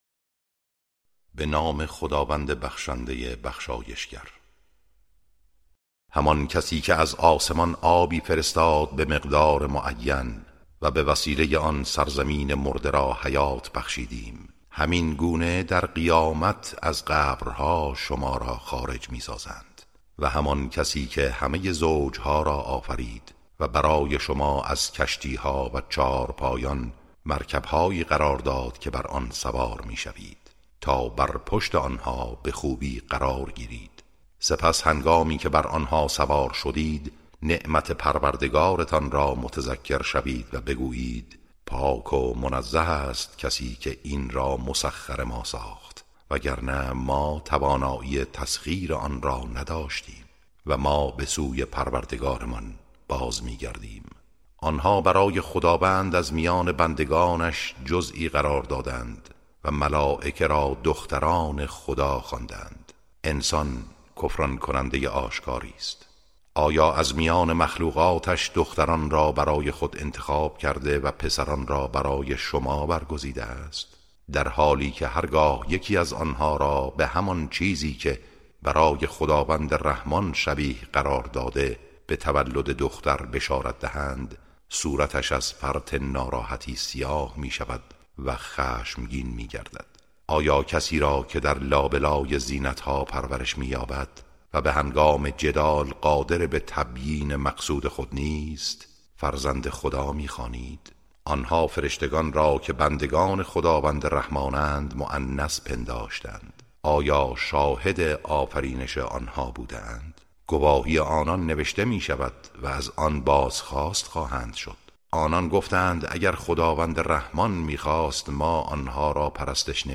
ترجمه سوره(زخرف)